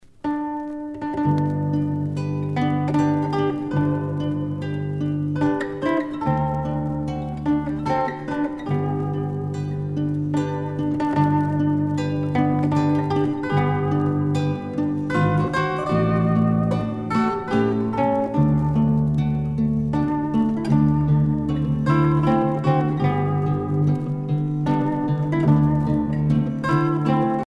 danse : valse lente